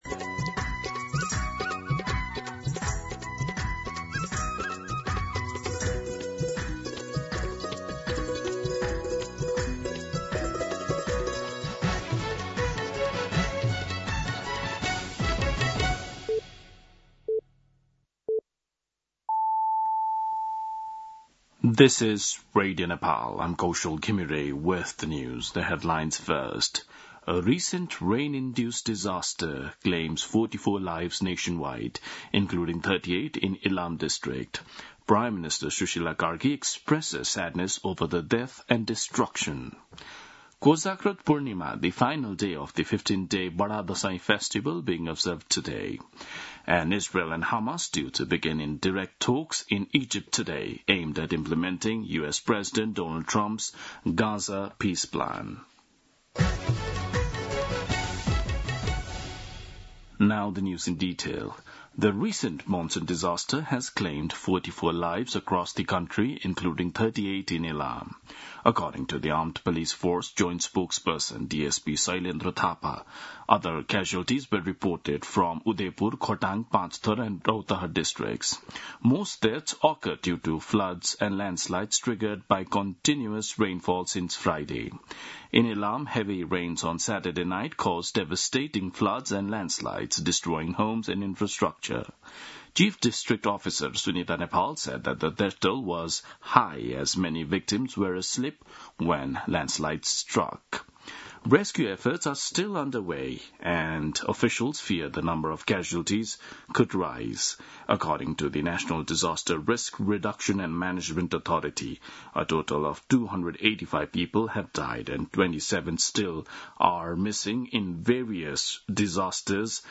दिउँसो २ बजेको अङ्ग्रेजी समाचार : २० असोज , २०८२
2-pm-English-News-1.mp3